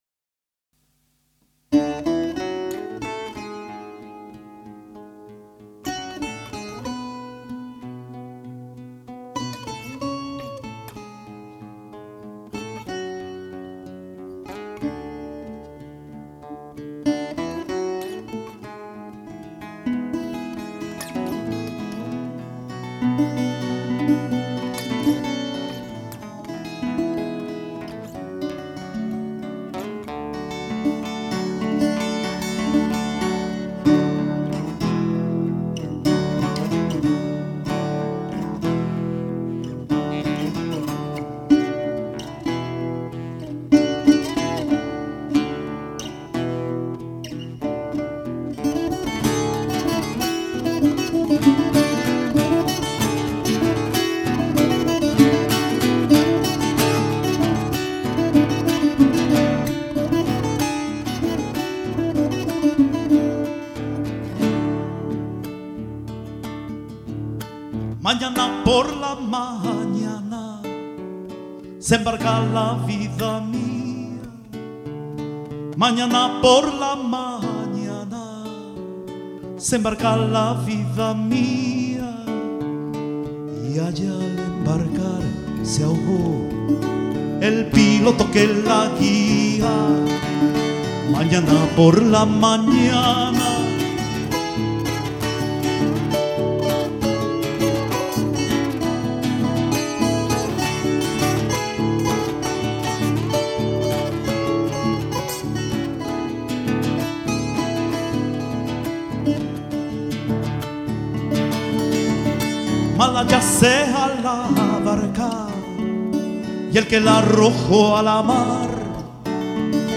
voce, chitarra, tiple, charango
sax tenore e soprano. flauti
Registrato a Milano - CSOA Leoncavallo il 7 marzo 2000